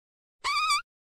Tiny Wet Fart